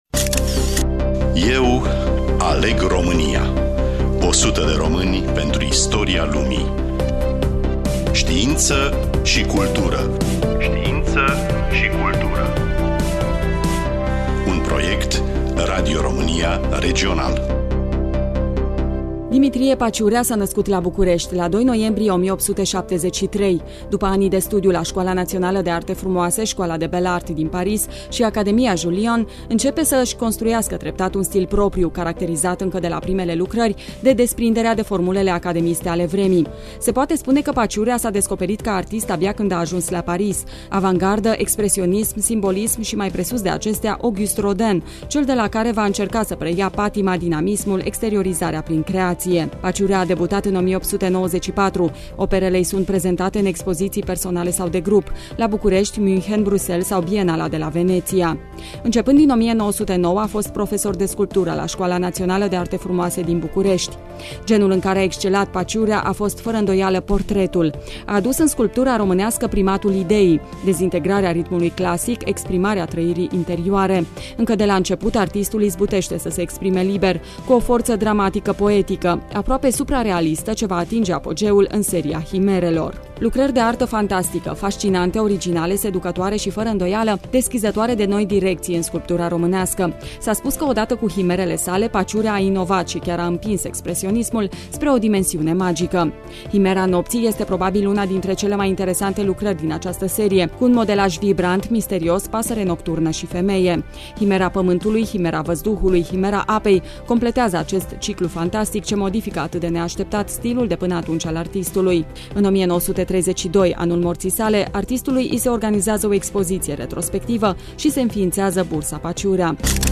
Studioul: Radio România Tg.Mureş